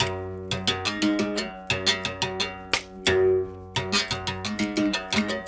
32 Berimbau 10.wav